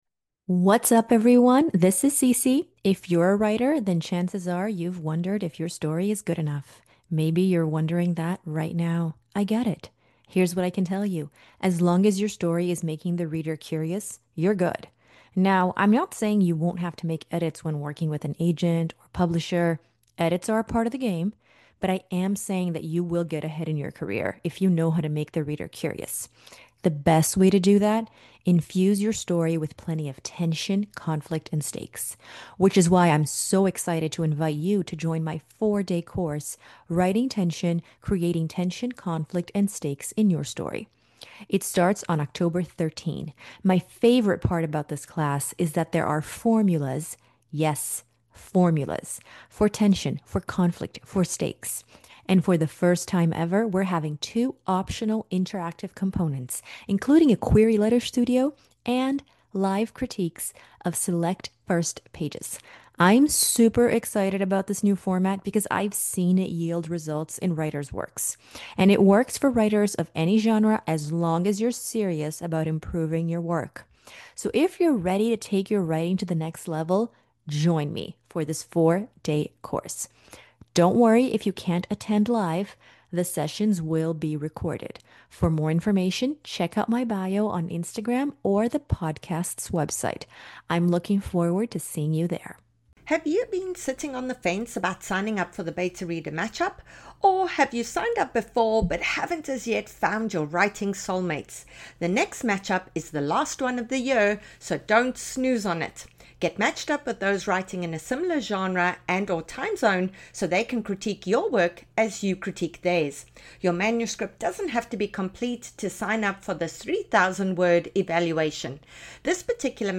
In this episode of Books with Hooks, hosts